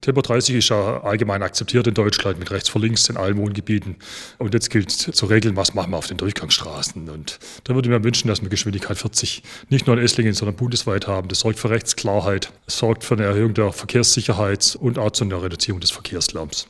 Matthias Klopfer, Oberbürgermeister von Esslingen